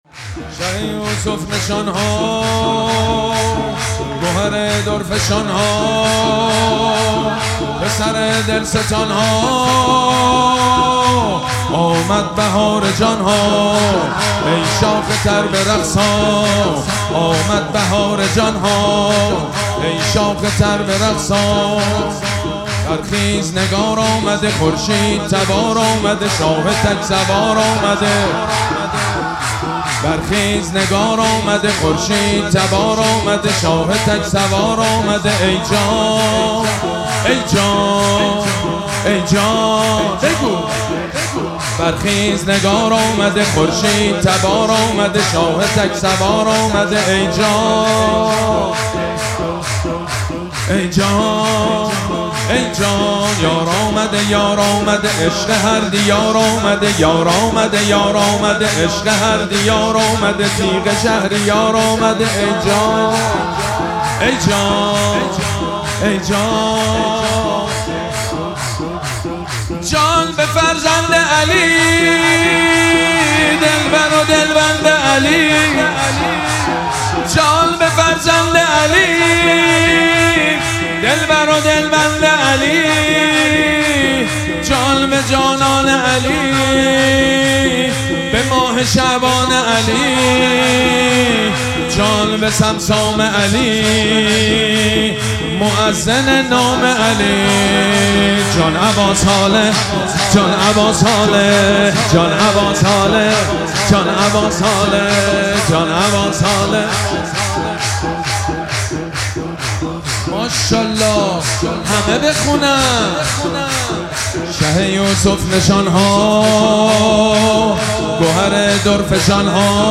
مراسم جشن ولادت حضرت صاحب الزمان (عج)
حسینیه ریحانه الحسین سلام الله علیها
سرود
حاج سید مجید بنی فاطمه